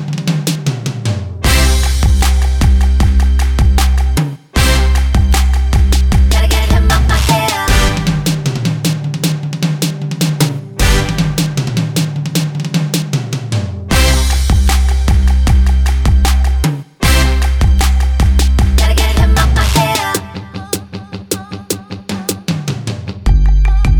Duet Version No Count Pop (2010s) 3:54 Buy £1.50